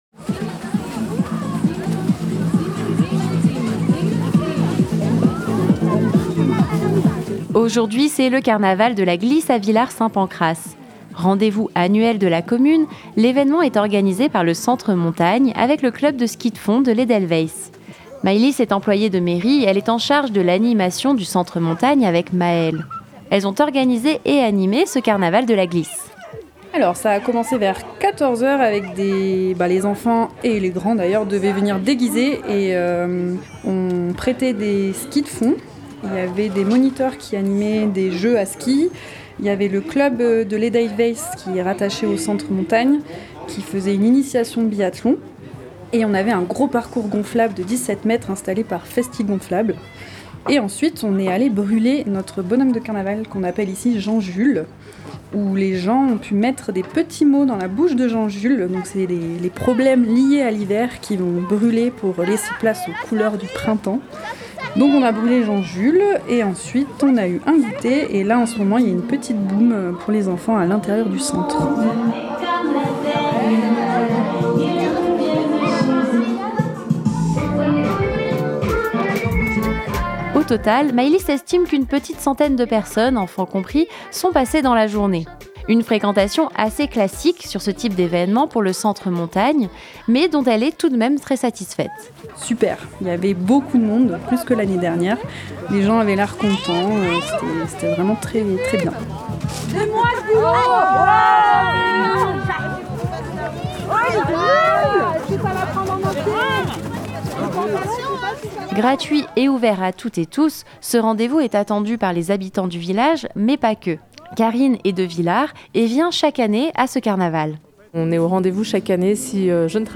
A Villard Saint Pancrace, le Carnaval de la glisse est un rendez-vous est annuel. Cette année, il se déroulait le mercredi 26 février au Centre Montagne. Reportage sur Fréquence Mistral Briançon.